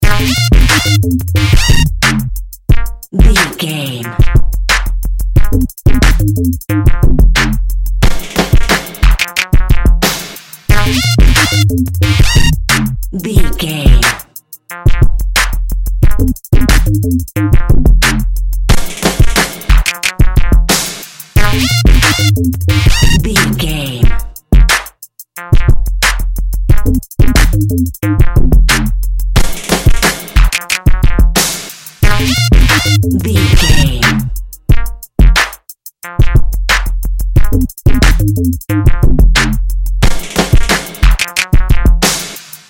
Aeolian/Minor
B♭
chilled
laid back
groove
hip hop drums
hip hop synths
piano
hip hop pads